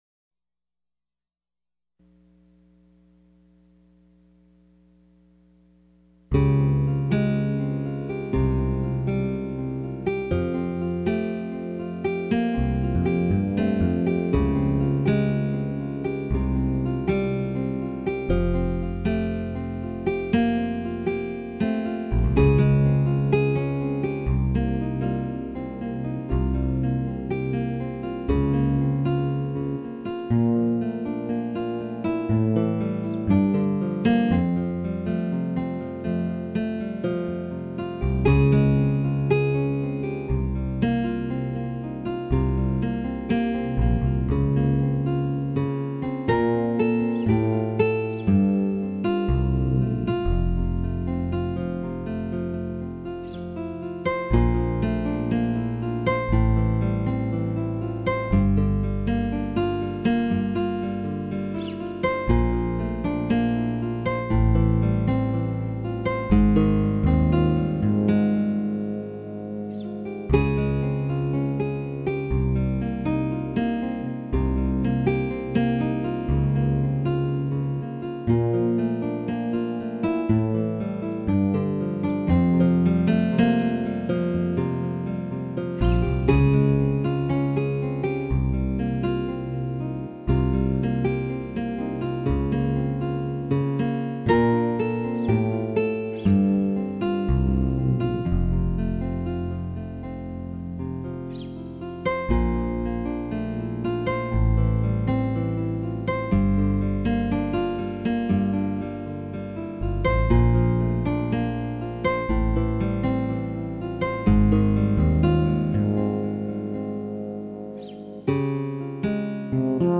the second is the instrumental reprise